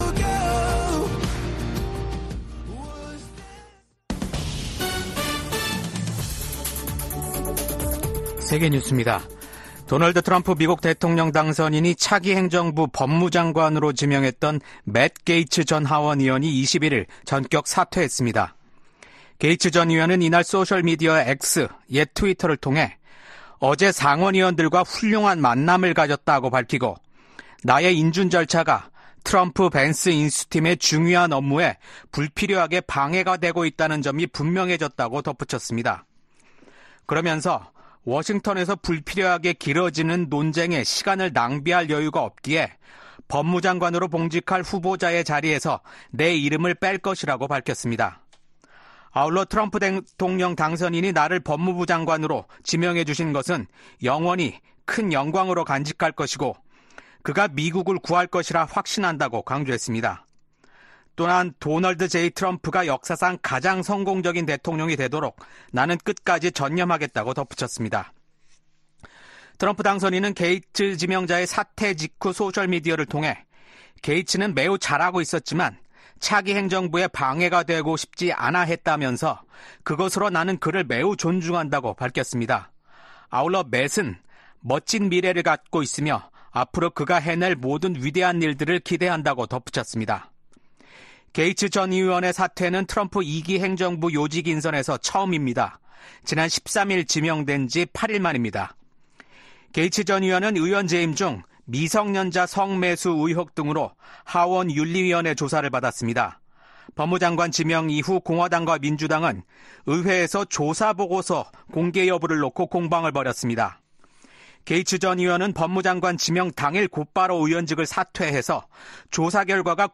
VOA 한국어 아침 뉴스 프로그램 '워싱턴 뉴스 광장'입니다. 미국 국무부는 우크라이나 전선에 배치된 북한군이 합법적인 군사 표적임을 재확인했습니다. 약 2년 전 만료된 북한인권법 연장을 승인하는 법안이 미국 하원 본회의를 통과했습니다. 유엔총회 제3위원회가 20년 연속 북한의 심각한 인권 상황을 규탄하는 결의안을 채택했습니다.